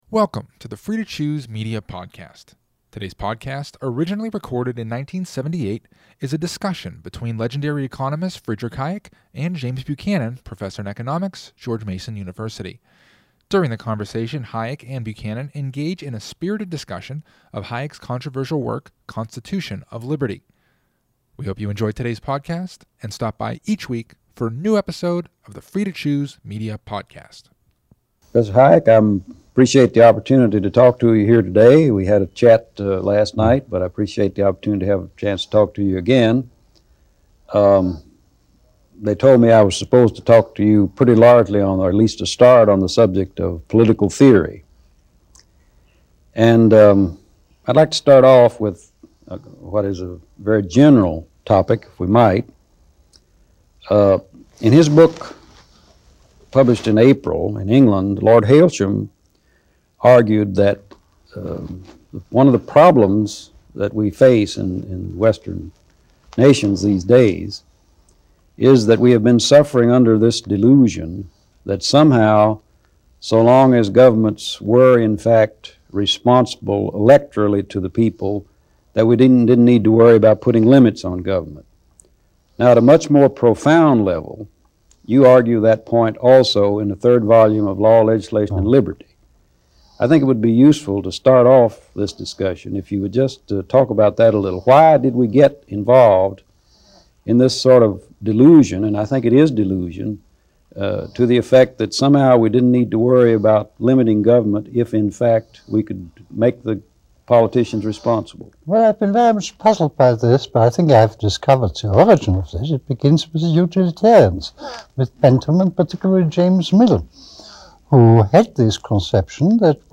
Two of the greatest economic minds over the past 50 years, Friedrich Hayek and James Buchanan, sound off in Part 1 of this 1978 recording covering the American Constitution, the role of government, and several other topics. Hear their unique perspectives that still resonate within our society over 40 years later.